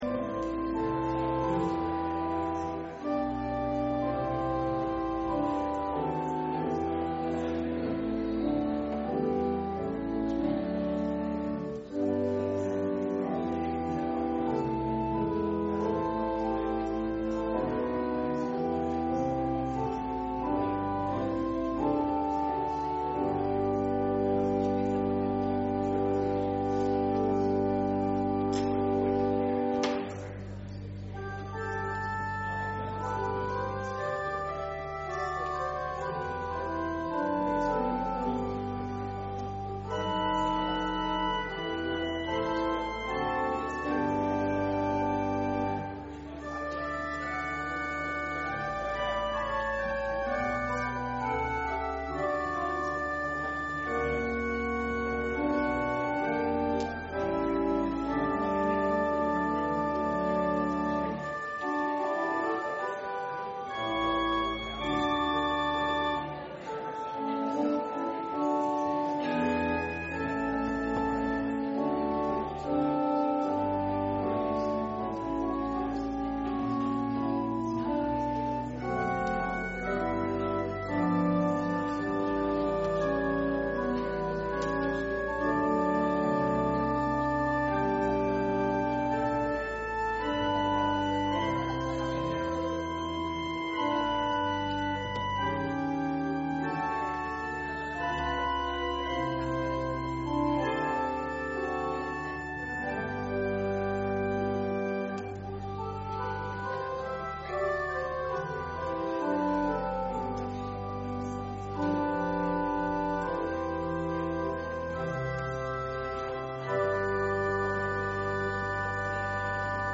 Public Reading of Holy Scripture
Service Type: Sunday Morning